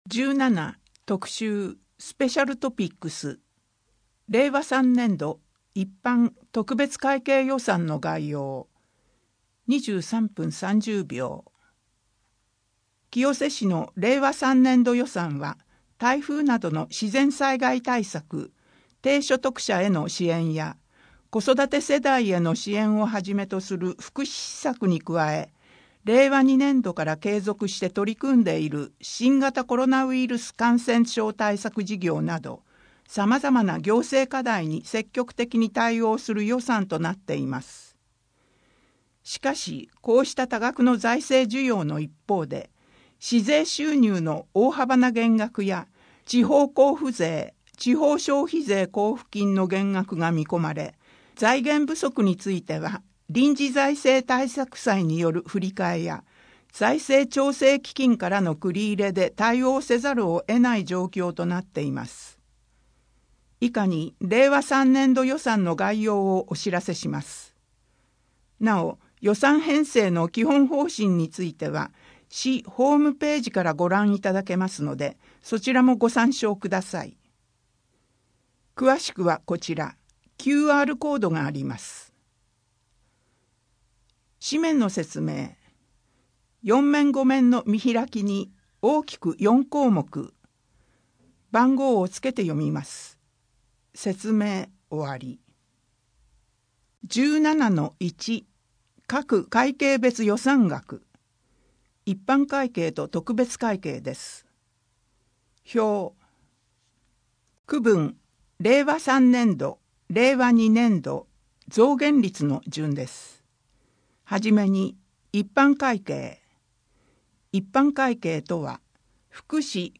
迷ったら「＃7119」 寄付・寄贈 多摩六都科学館の催し物 郷土博物館からのお知らせ 春の藍染教室 ミュージアム・シアター1 博物館事業スタンプラリー2020～2021 図書館のイベント・お知らせ 図書館事業「元町こども図書館 本のお楽しみ袋」 図書館ホームページのセキュリティを強化します 清瀬けやきホール・コミュニティプラザひまわりの催し物 人口と世帯 声の広報 声の広報は清瀬市公共刊行物音訳機関が制作しています。